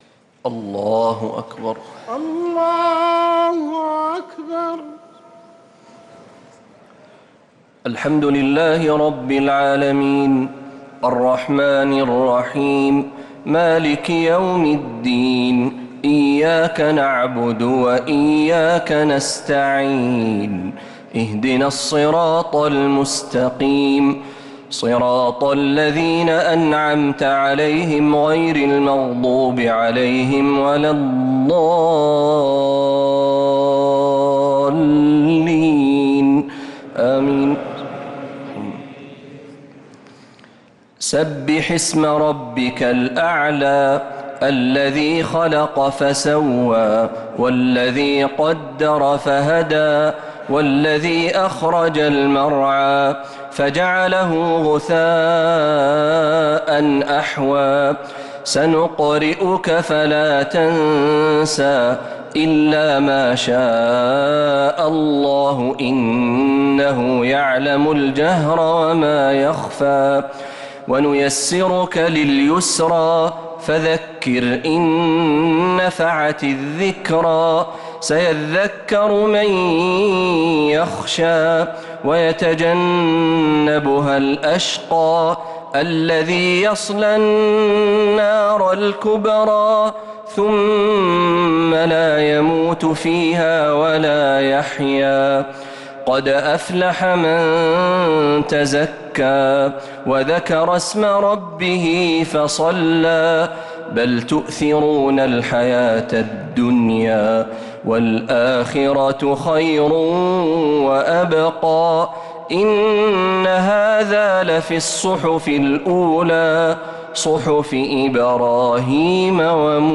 الشفع و الوتر ليلة 11 رمضان 1446هـ | Witr 11th night Ramadan 1446H > تراويح الحرم النبوي عام 1446 🕌 > التراويح - تلاوات الحرمين